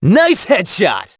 hotshot_kill_06.wav